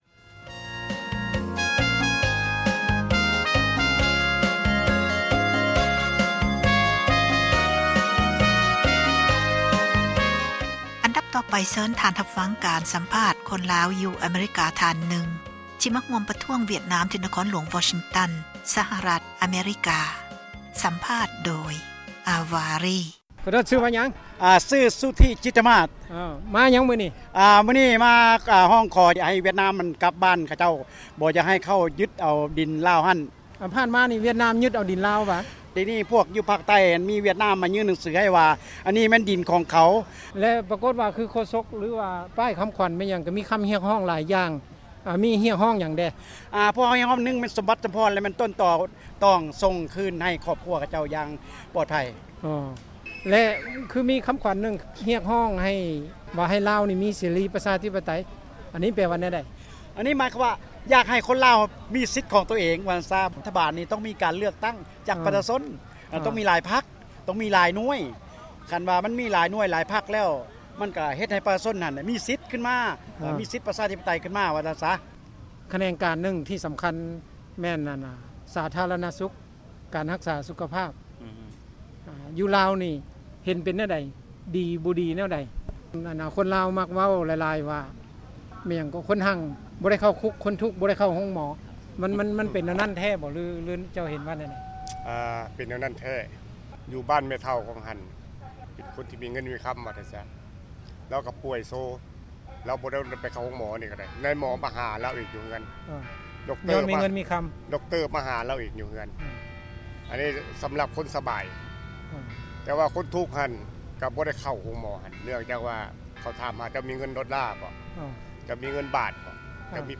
ການສໍາພາດ ຊາວລາວ ອາເມຣິກັນ ທີ່ ເຂົ້າຮ່ວມ ປະທ້ວງ ວຽດນາມ ທີ່ ຢູ່ ນະຄອນຫລວງ Washington ສະຫະຣັຖ ອະເມຣິກາ.